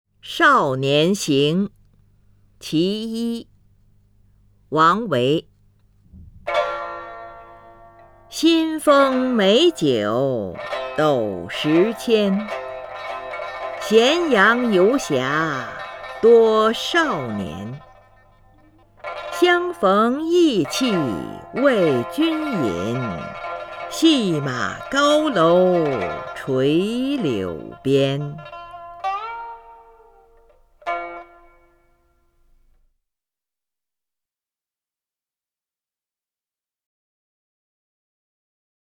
林如朗诵：《少年行四首·其一》(（唐）王维) （唐）王维 名家朗诵欣赏林如 语文PLUS